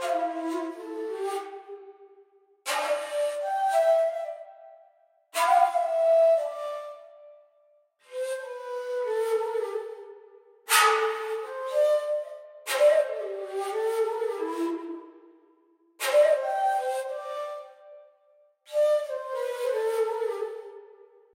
描述：Ezweni (This World) 我演奏并录制了RECORDER FLUTE。用Cool Edit pro做了一个循环。
标签： 172 bpm House Loops Flute Loops 918.25 KB wav Key : F
声道立体声